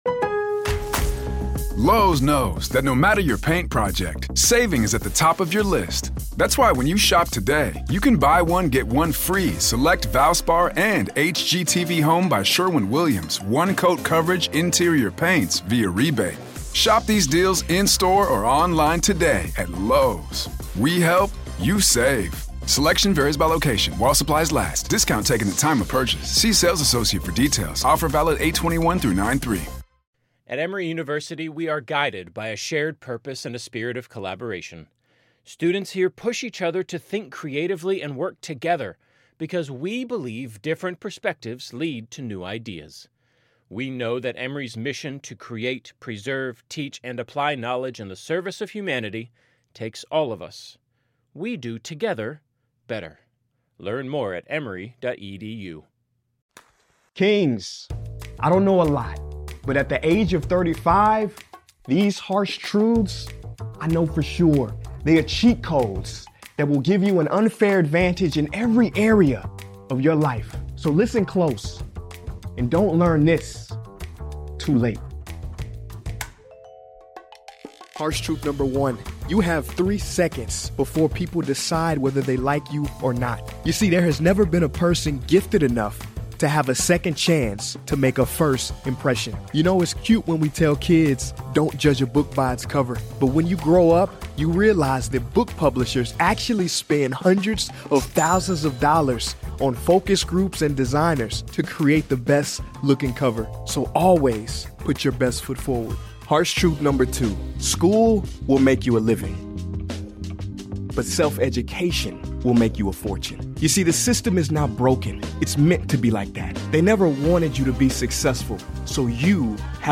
Speaker: Prince Ea